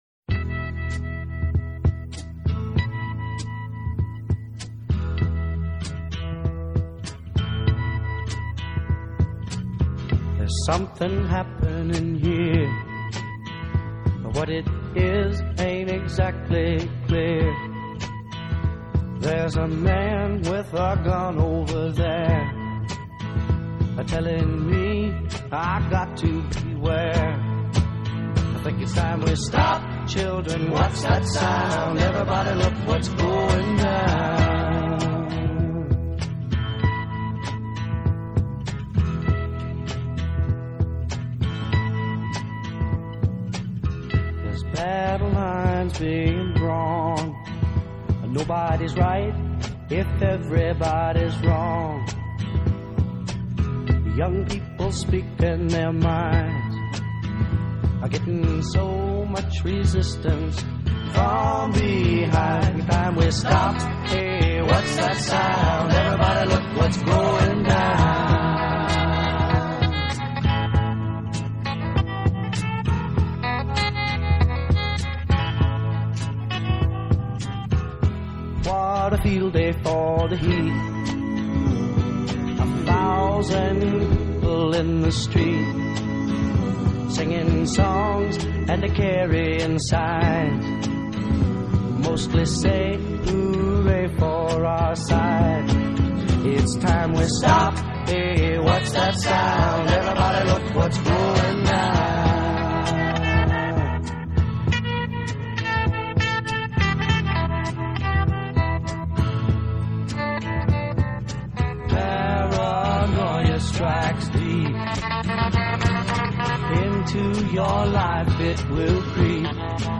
Rock, Pop, Soul